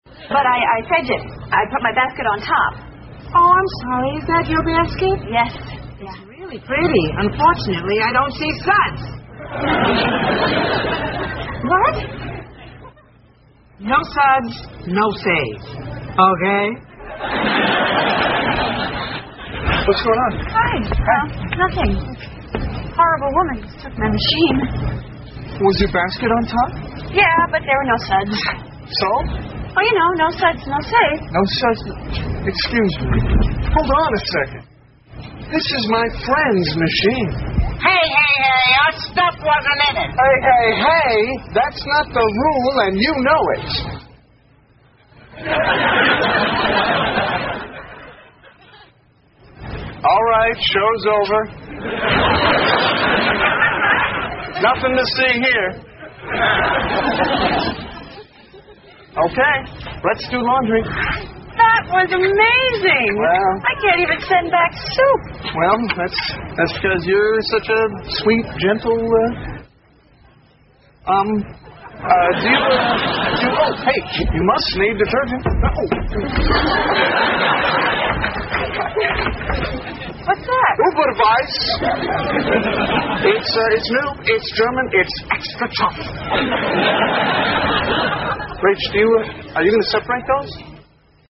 在线英语听力室老友记精校版第1季 第54期:洗衣服(7)的听力文件下载, 《老友记精校版》是美国乃至全世界最受欢迎的情景喜剧，一共拍摄了10季，以其幽默的对白和与现实生活的贴近吸引了无数的观众，精校版栏目搭配高音质音频与同步双语字幕，是练习提升英语听力水平，积累英语知识的好帮手。